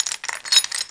chainRattle25.mp3